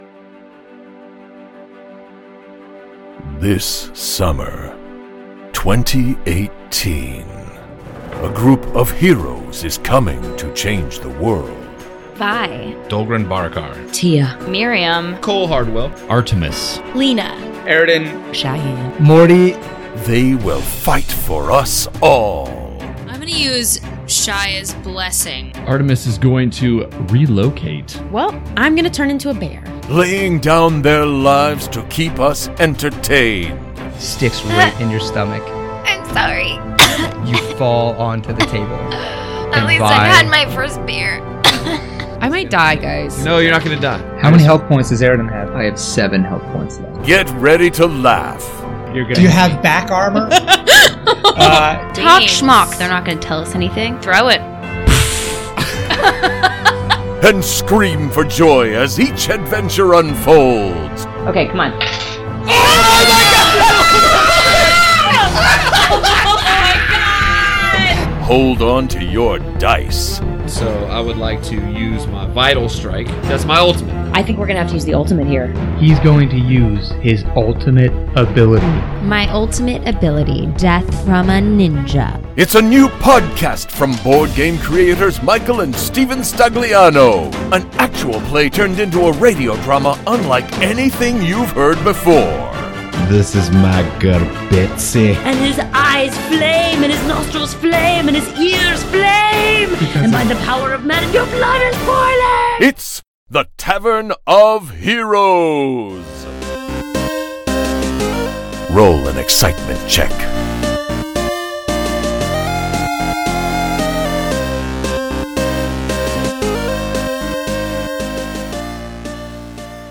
The first episode from the Tavern of Heroes has a very laid back feel and each character has a different accent and personality.
The chip tune music chosen for transition points during the show and the narrative of the adventure brought me back to my console hack and slash/rpg adventure game days.
P.S. I’ve attached a trailer of the show below so you can get a taste of what the stories will be like!
tavern-of-heroes-trailer.mp3